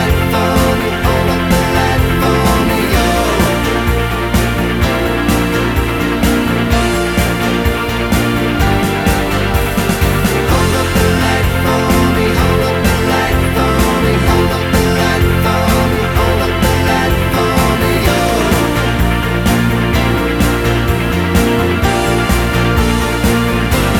No Backing Vocals Pop